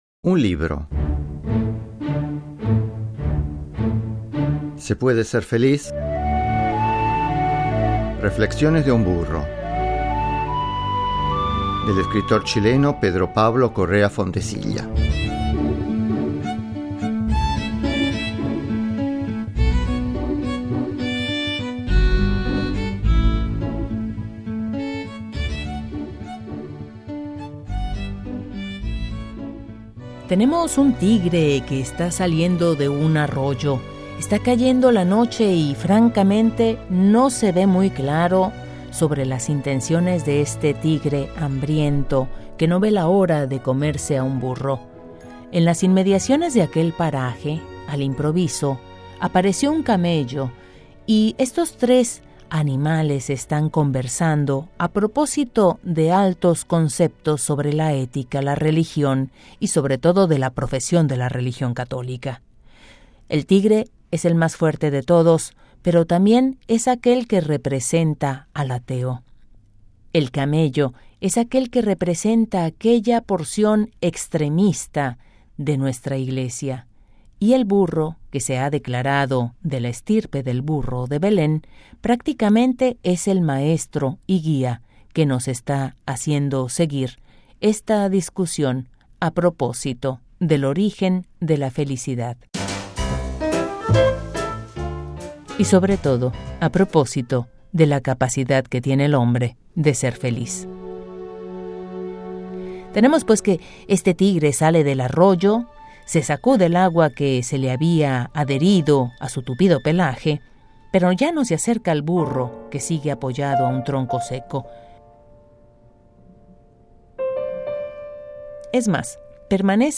Adaptación Radiofónica de un libro: